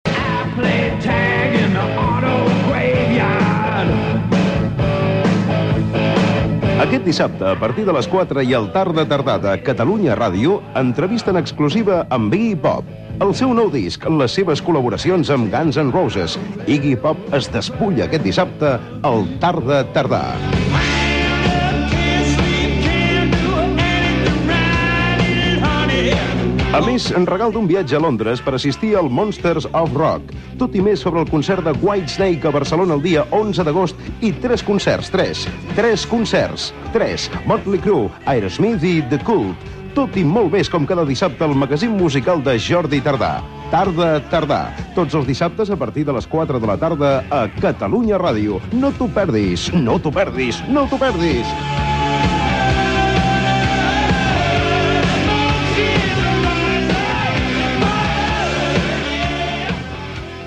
Promoció del programa del proper dissabte
Musical